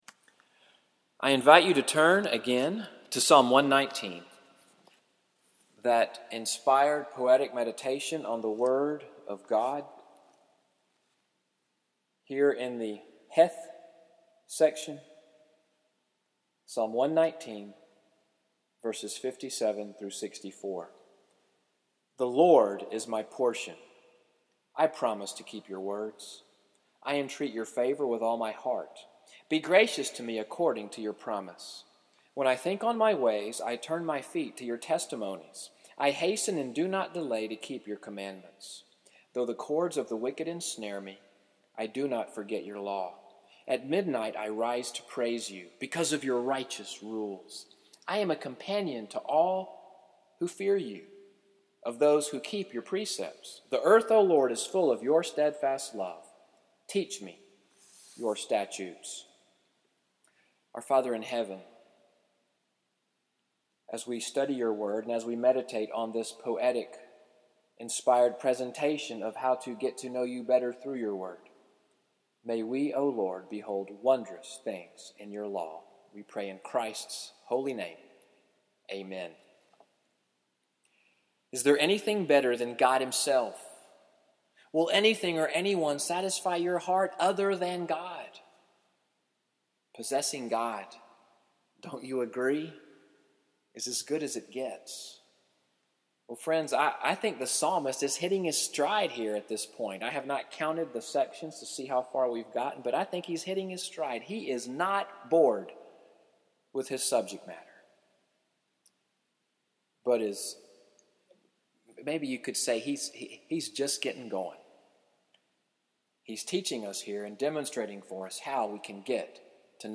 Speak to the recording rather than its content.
Evening Worship at NCPC-Selma, audio from the sermon, “Getting to Know God,” June 3, 2018, from the series: Walking in the Word (22:49).